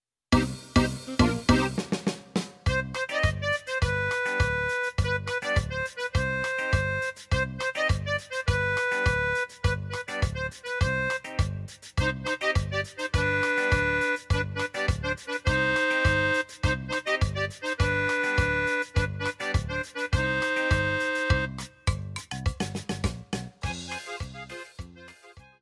23-Cumbia-Nrt1.mp3